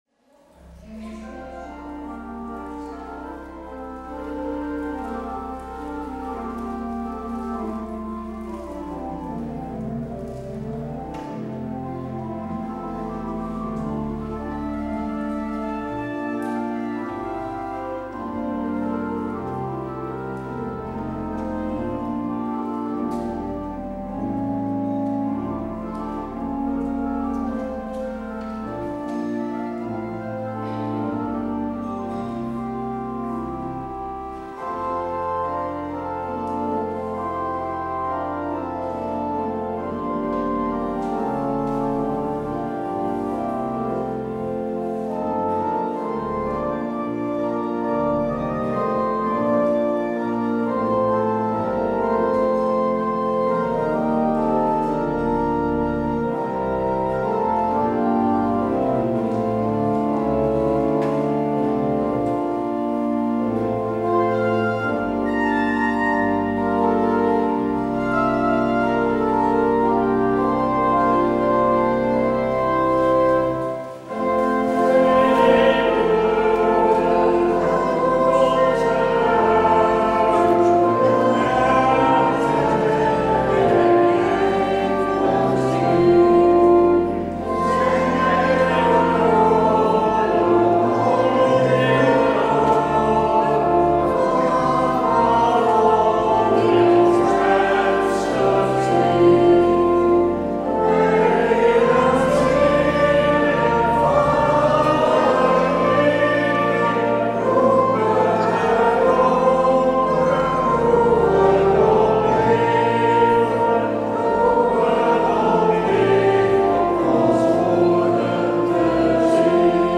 Luister deze kerkdienst terug
Het openingslied is Lied 624 LB, Christus onze Heer verrees.